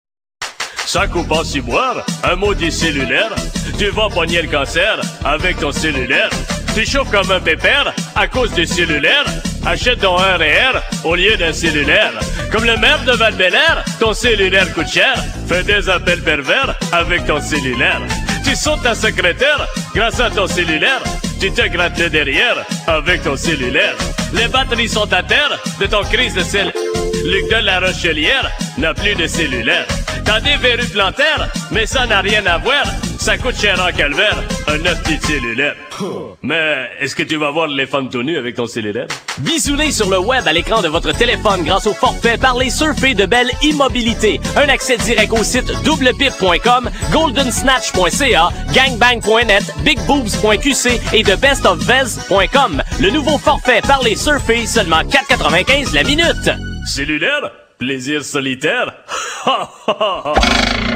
Petite chanson Vantant les avantages du telephone portable